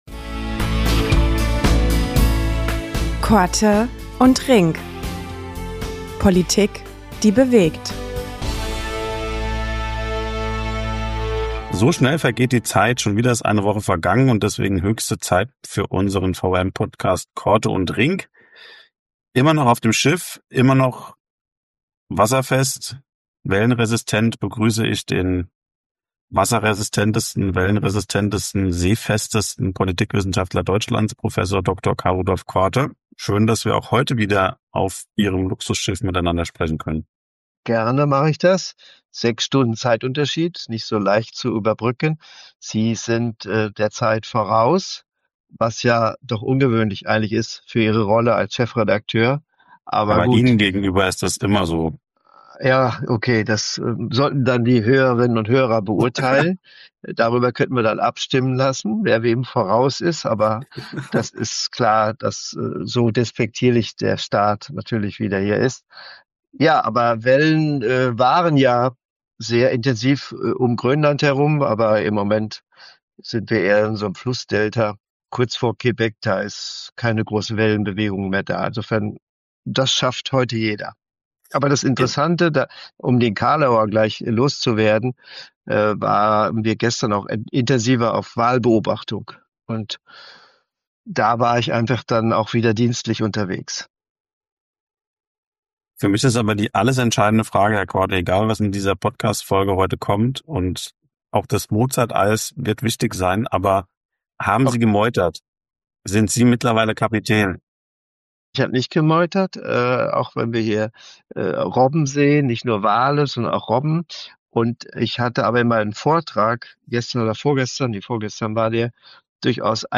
begegnen sich ein letztes Mal an Bord der MS Europa